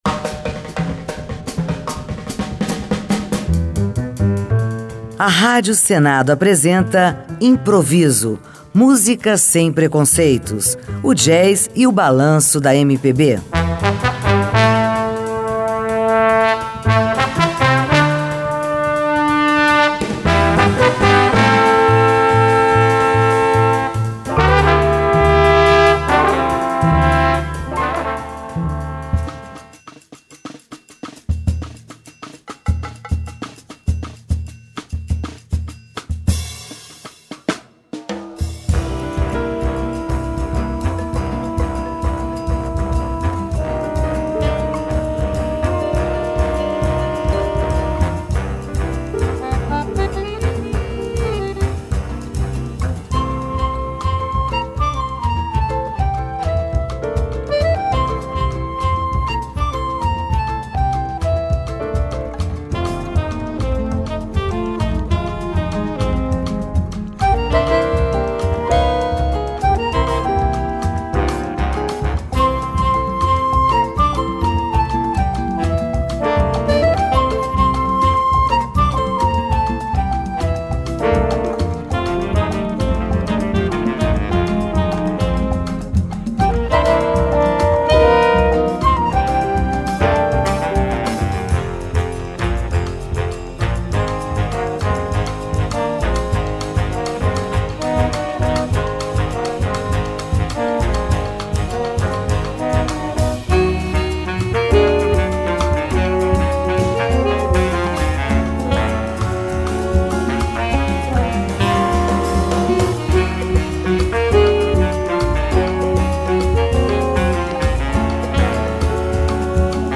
um songbook instrumental, em clave de jazz
gravado ao vivo em 1987, em Los Angeles.